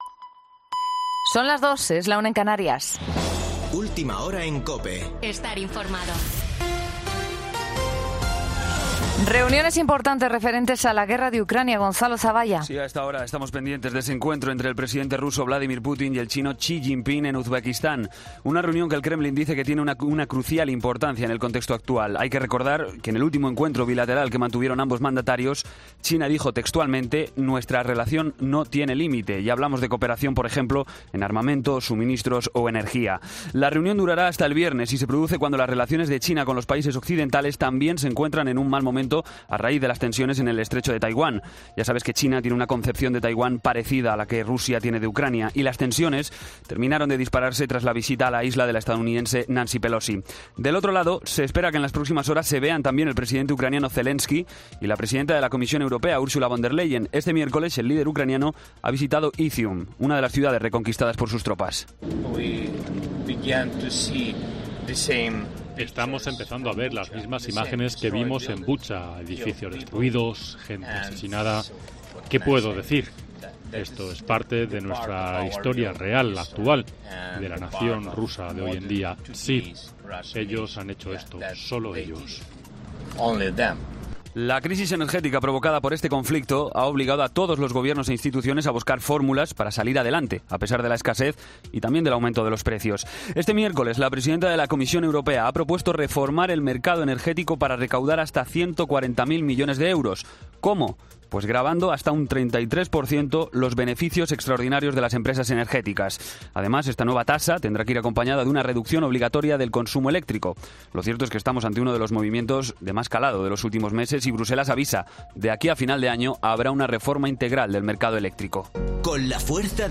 Boletín de noticias COPE del 15 de septiembre a las 02:00 horas